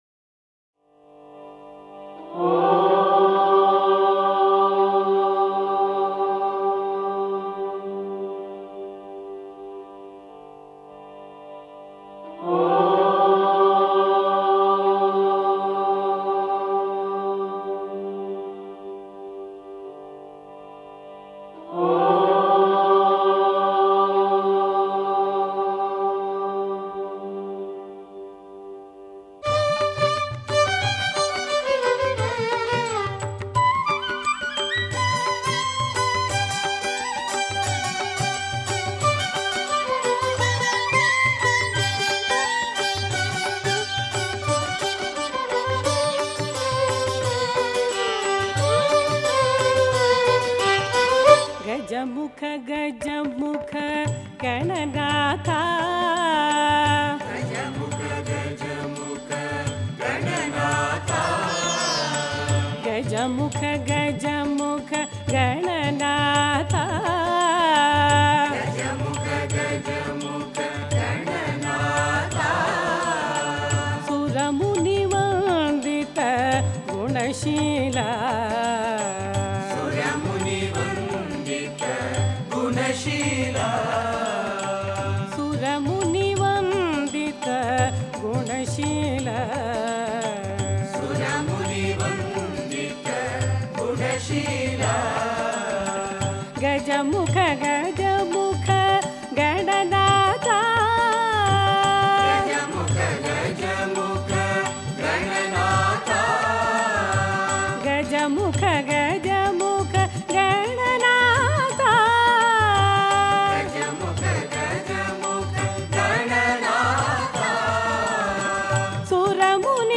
Home | Bhajan | Bhajans on various Deities | Ganesh Bhajans | 07 GAJAMUKHA GAJAMUKHA